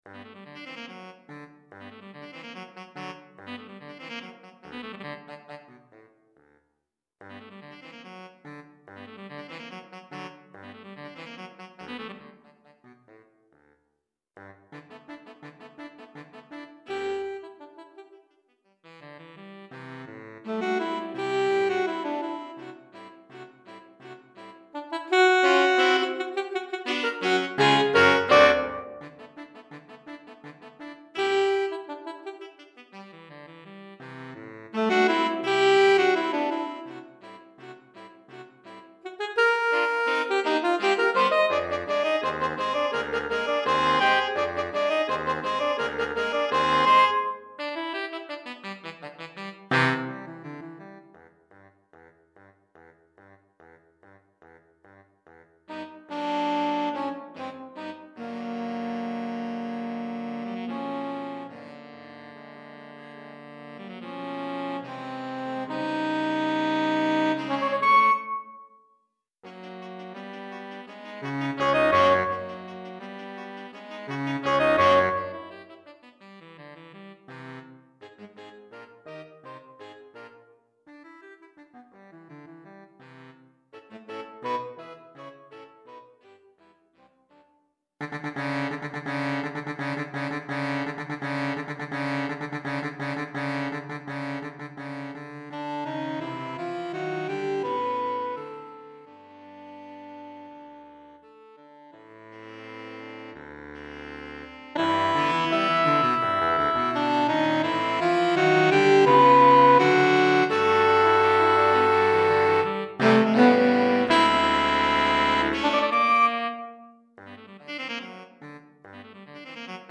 Saxophone Soprano, Alto, Ténor et Baryton